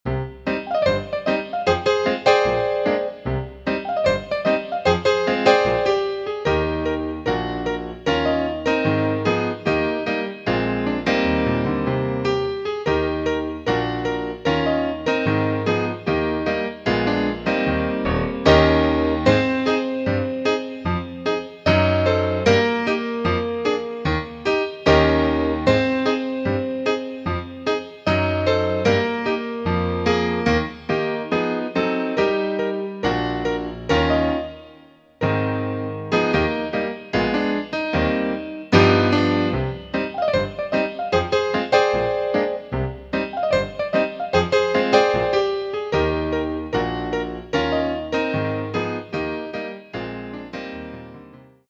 instrumental music cues can also be used to